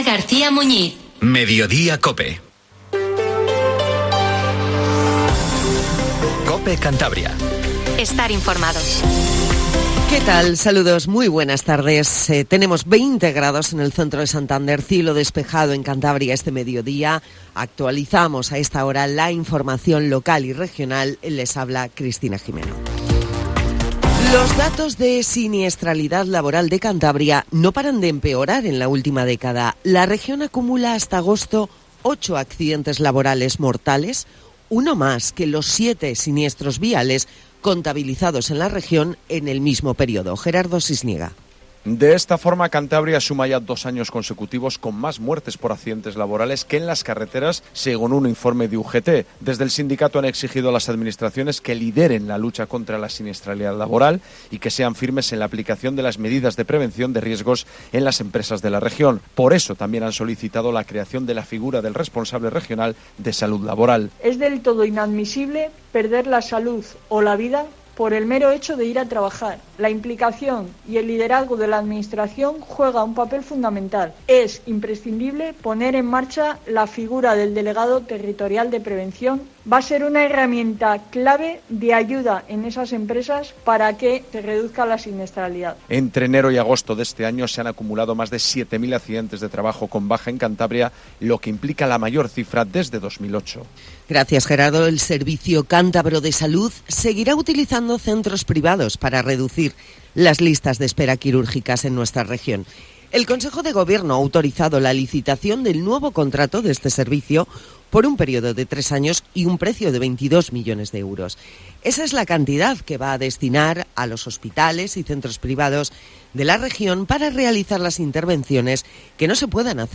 Informtivo Mediodía COPE CANTABRIA